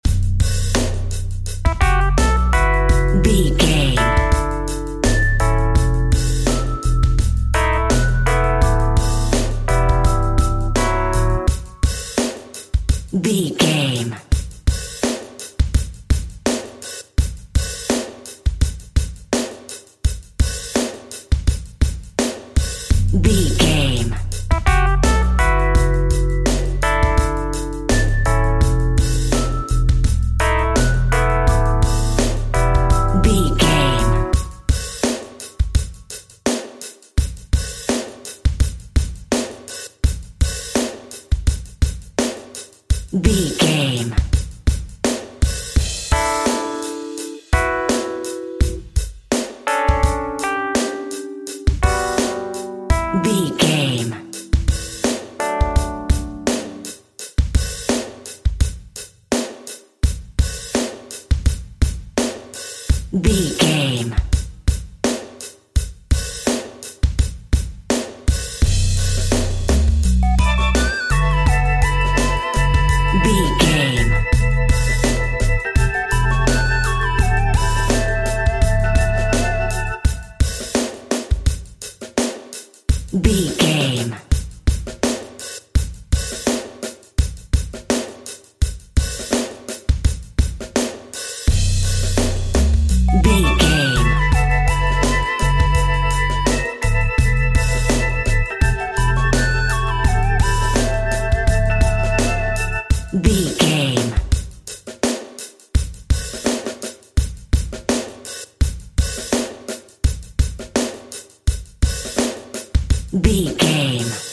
Aeolian/Minor
calm
happy
electric guitar
bass guitar
drums
percussion
synthesiser
Funk
disco funk
soul jazz
hammond organ
fender rhodes